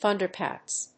/ˈθʌndɝˌkæts(米国英語), ˈθʌndɜ:ˌkæts(英国英語)/